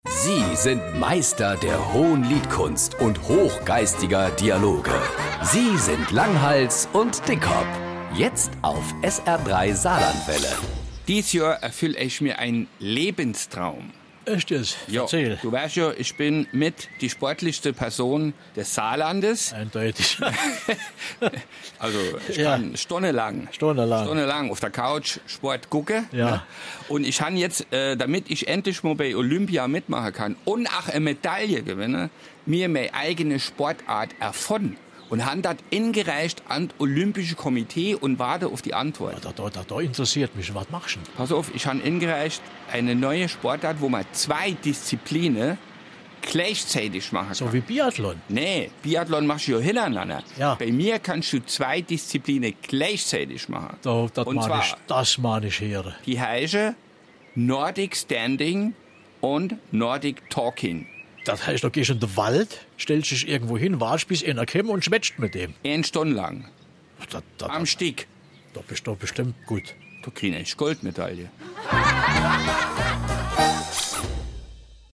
Die sprachlichen Botschafter Marpingens in der weiten saarländischen Welt. Philosophisch, vorder-, tief- und hintergründig lassen sie uns teilhaben an ihren mikrokosmischen An- und Einsichten.
… continue reading 204 Episoden # Komödie